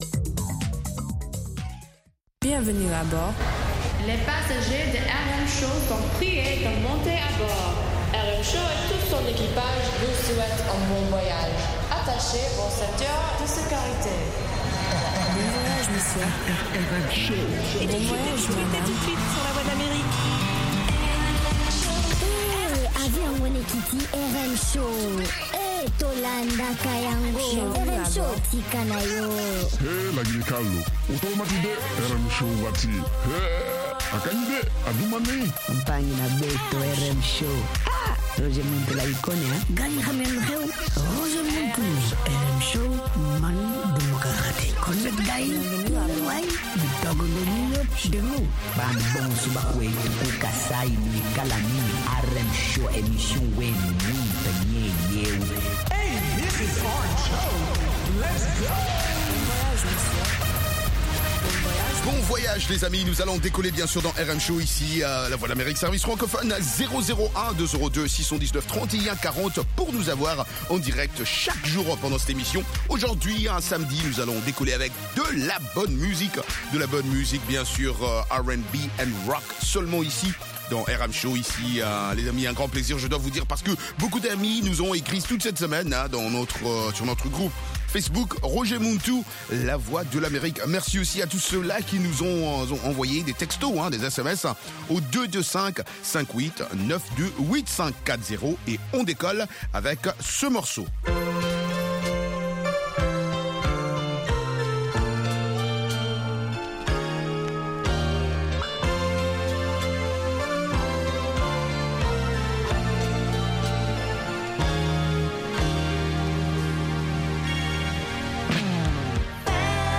RM Show - R&B et Rock